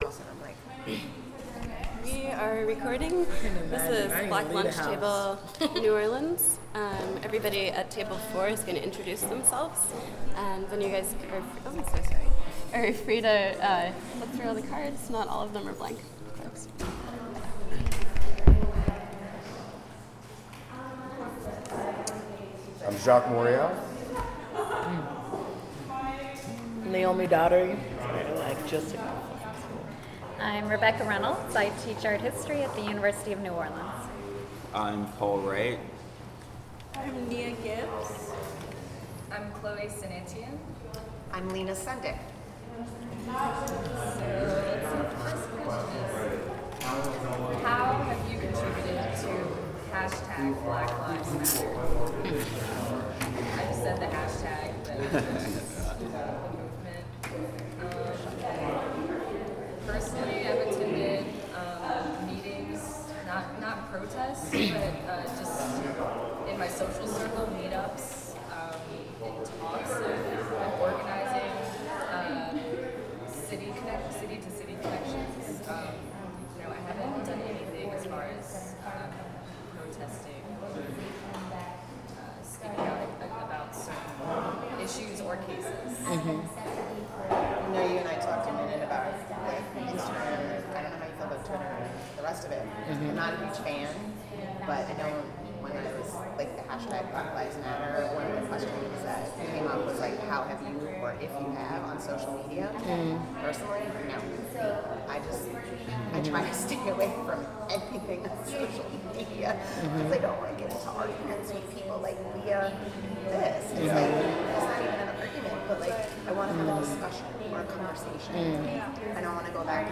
Type sound recording-nonmusical
Genre oral history